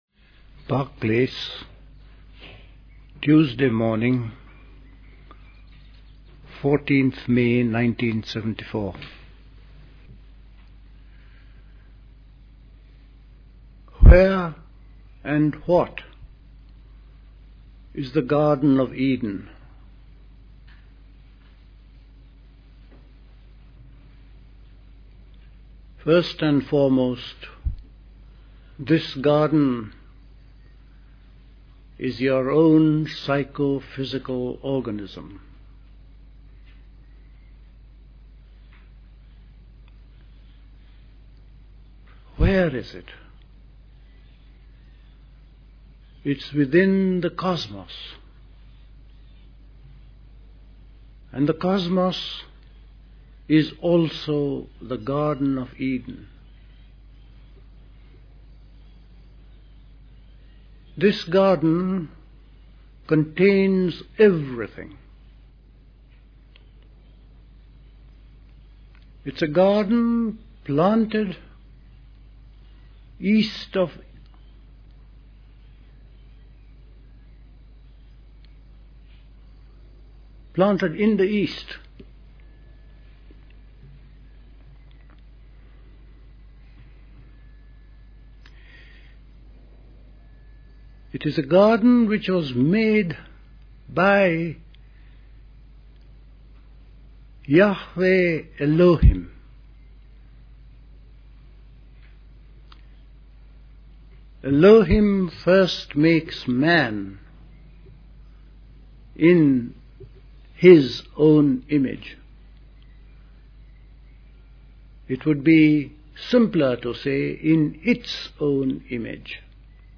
Recorded at the 1974 Park Place Summer School.